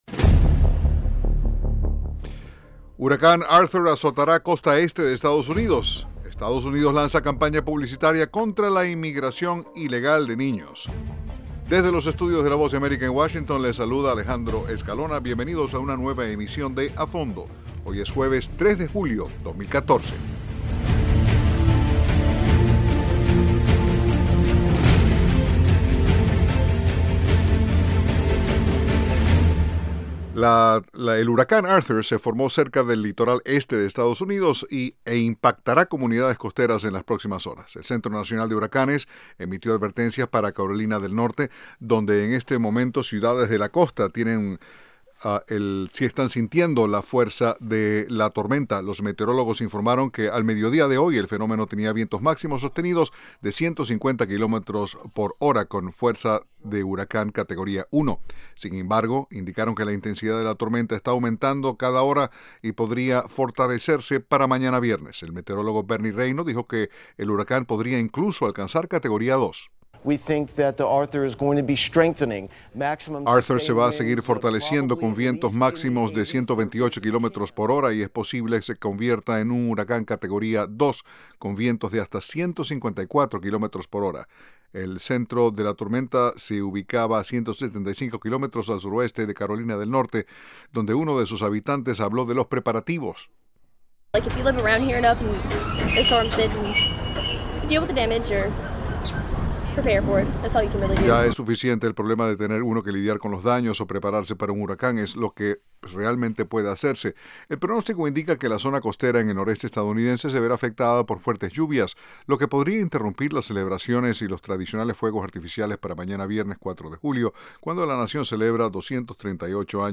De lunes a viernes, a las 8:00pm [hora de Washington], un equipo de periodistas y corresponsales analizan las noticias más relevantes.